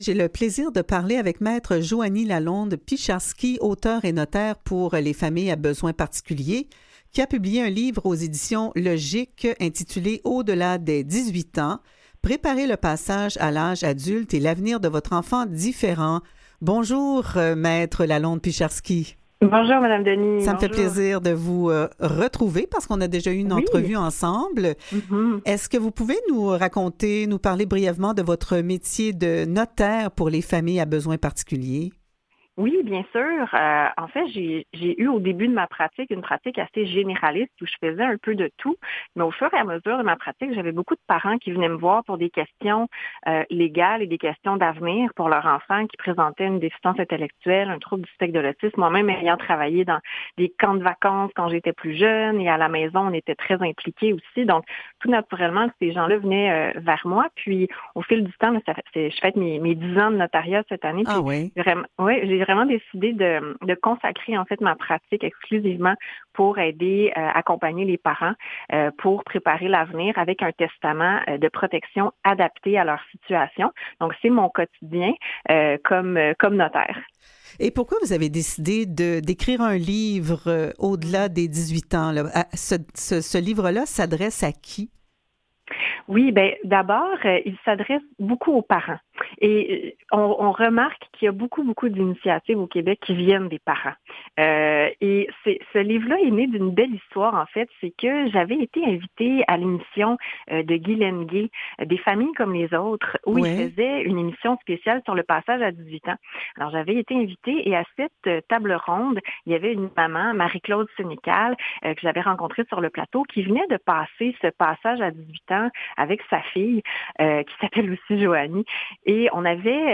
Revue de presse et entrevues du 1er octobre 2021